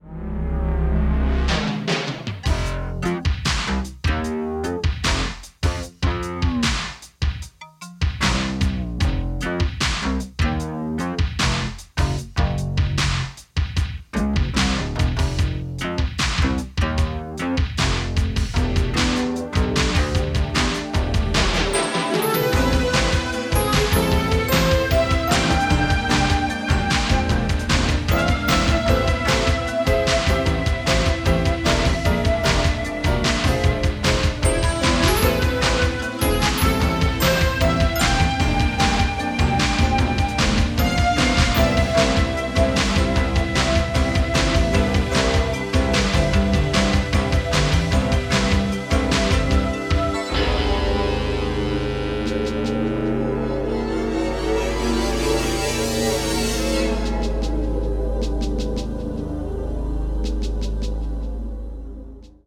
for a small orchestra